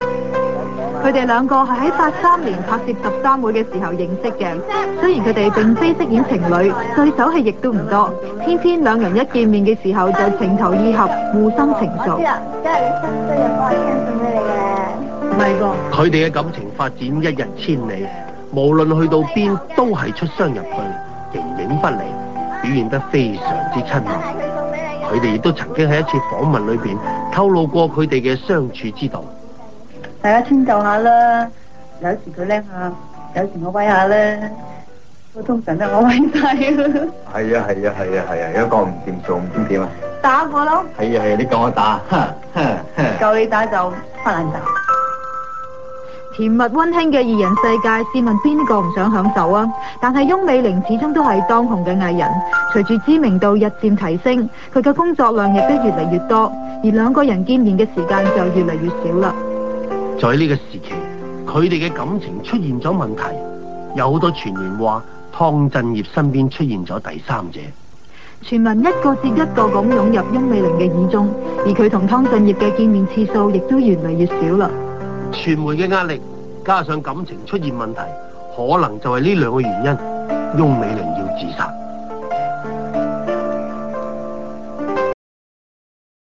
本影片回顧了一些翁美玲生前的片段，和訪問